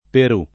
per2+] top. m. — perlopiù con p- minusc. nella locuz. valere un perù, «valere un tesoro»; ma talvolta maiusc.: quest’alternativa in bocca del commissario magniloquente vale un Perù [kU%St alternat&va im b1kka del kommiSS#rLo man’n’ilokU$nte v#le um per2] (F. Martini) — sp. Perú [per2]